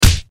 Punch5.wav